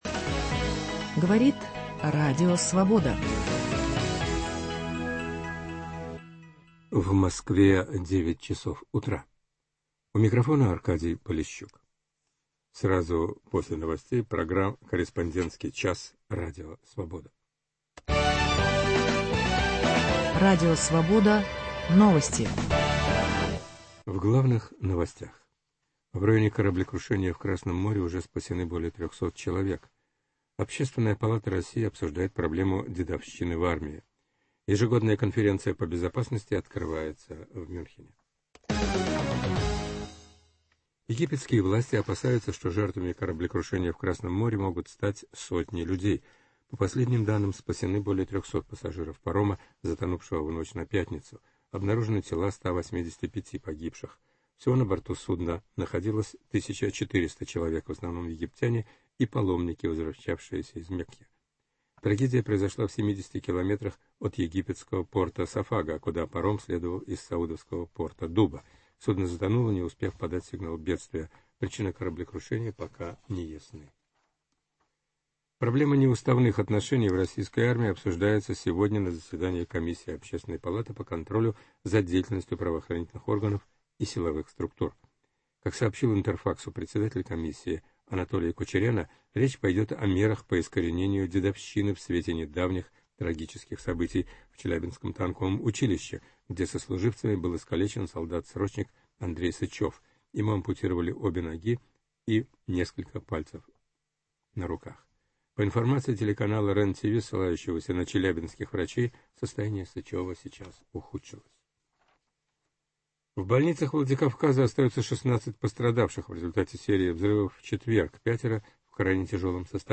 Еженедельная серия радиоочерков о жизни российской провинции. Авторы из всех областей России рассказывают о проблемах повседневной жизни обычных людей.